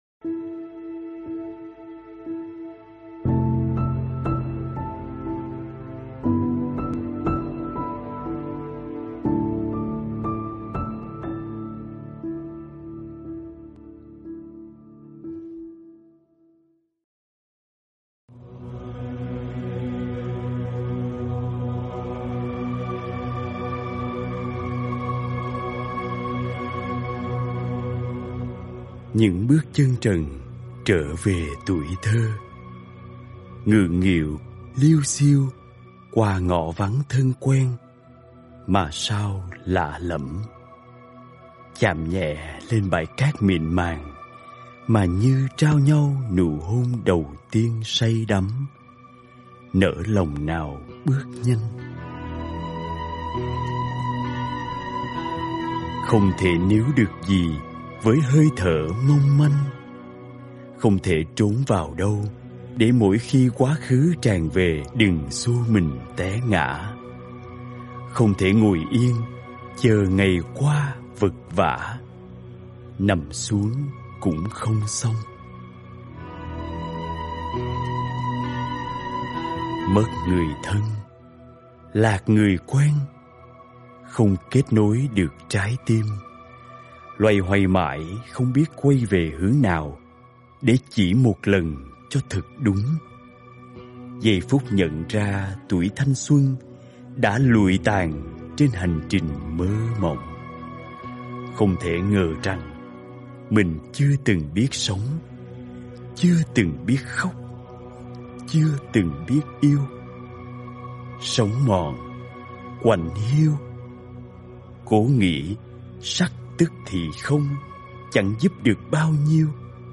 Thích Minh Niệm - Mp3 thuyết pháp